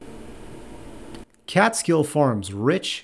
There is quite a bit of noise behind you. I made it worse on purpose.
Is that your computer?